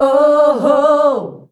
OOOHOO  D.wav